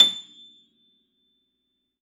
53f-pno25-A5.aif